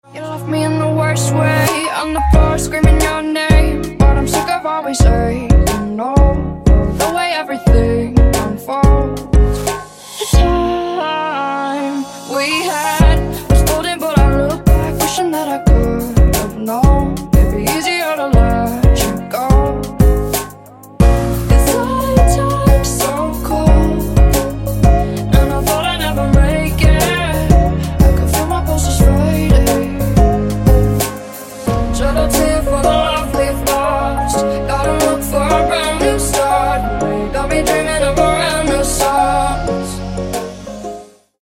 • Качество: 192, Stereo
поп
женский вокал
dance